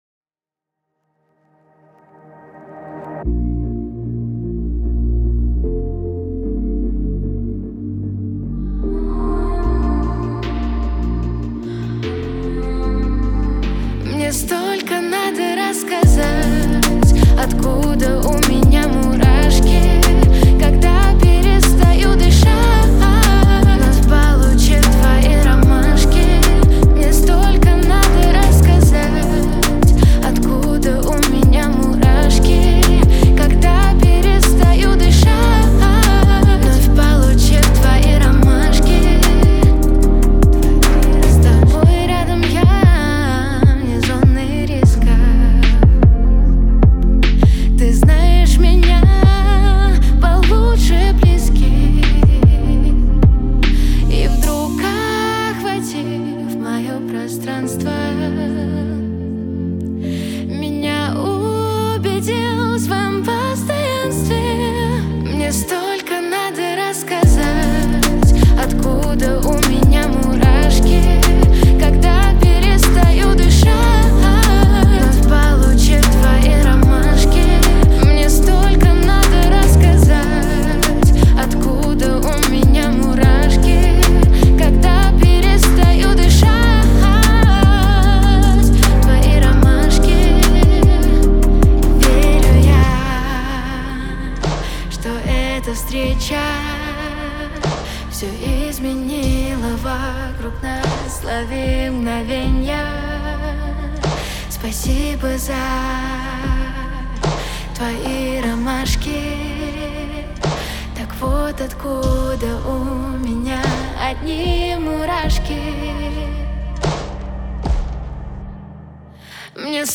это нежная и мелодичная песня в жанре поп